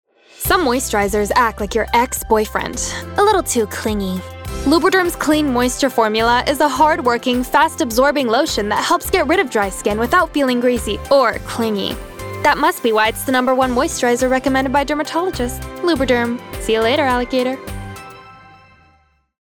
Commercial (2) - EN